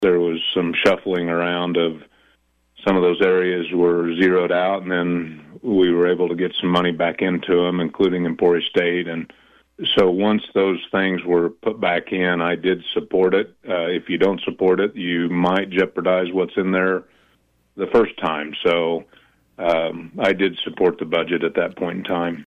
Schreiber and Argabright offered their comments during separate interviews on KVOE’s Morning Show Wednesday where another major talking point focused on the passage of Senate Bill 269, known as the “income tax trigger bill,” which aims to reduce individual and corporate income tax rates to as low as 4 percent.